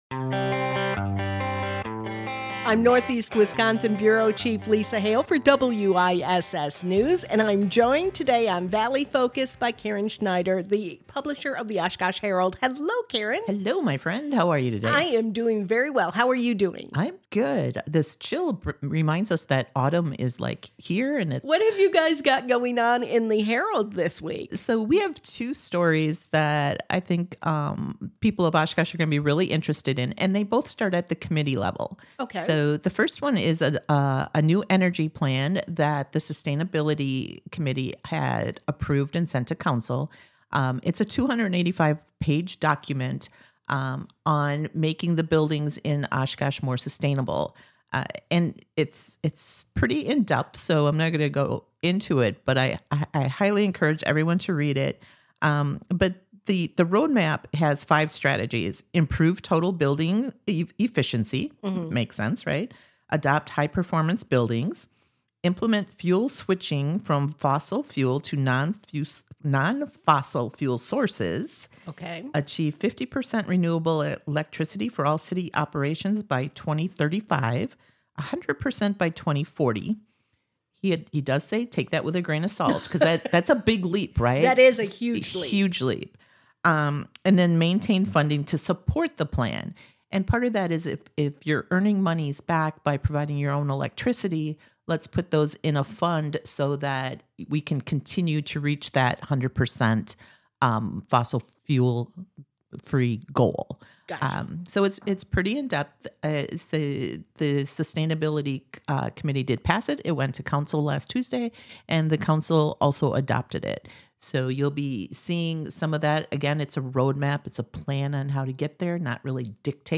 Conversations will range from entertainment to government to community involvement and more!